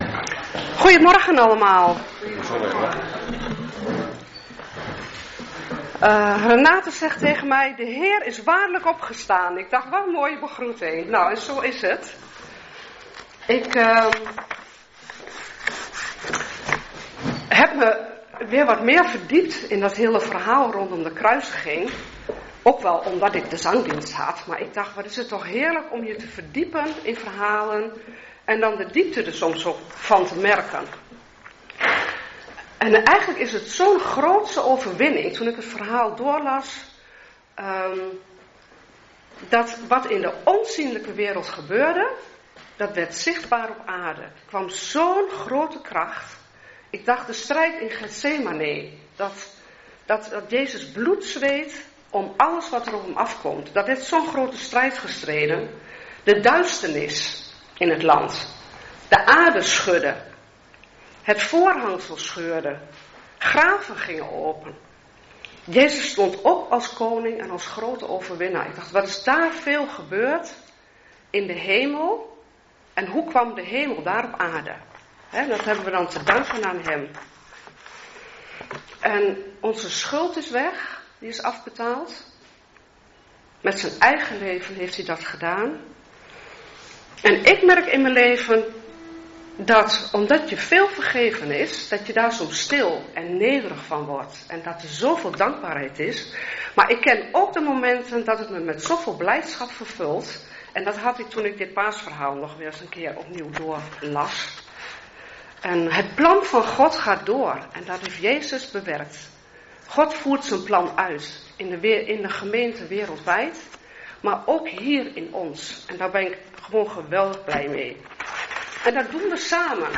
5 april 2026 paasdienst - Volle Evangelie Gemeente Enschede